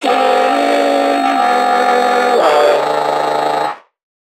NPC_Creatures_Vocalisations_Infected [87].wav